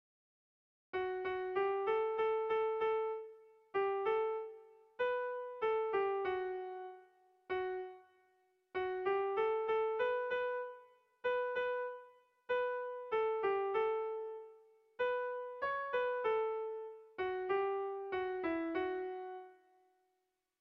Dantzakoa
A1A2